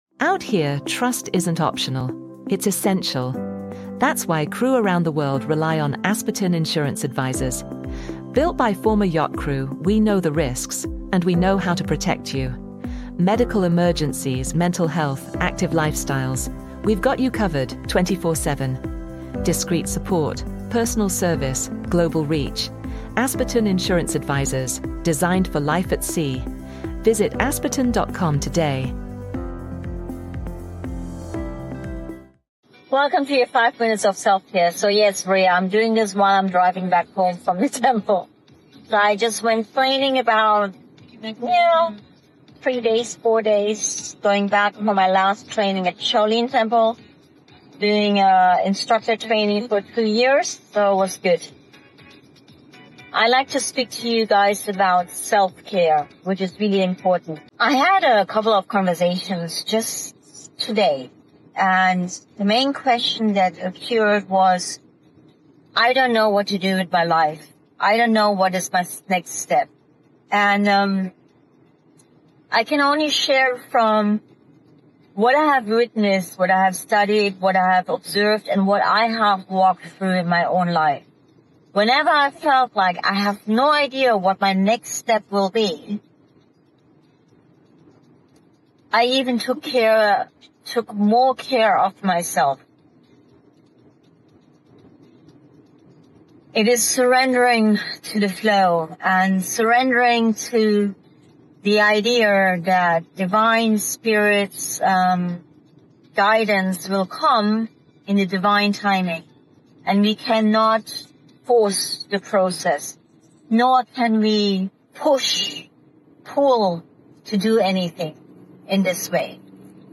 Recorded on the road